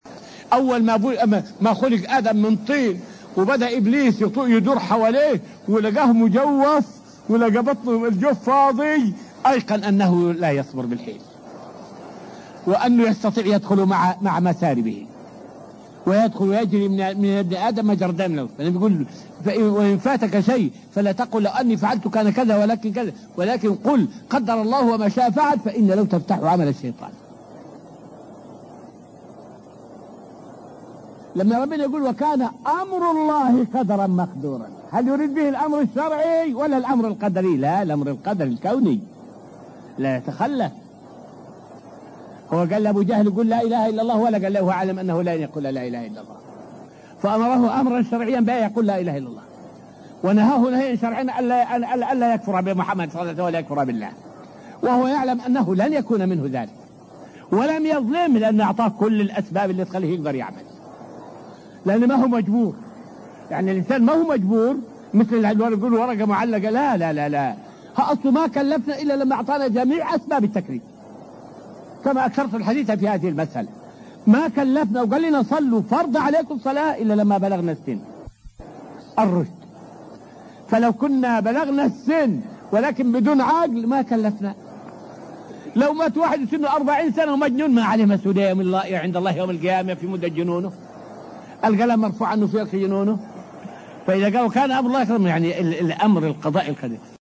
فائدة من الدرس السابع عشر من دروس تفسير سورة البقرة والتي ألقيت في المسجد النبوي الشريف حول تفسير آية {لا يكلف الله نفسًا إلا وسعها}.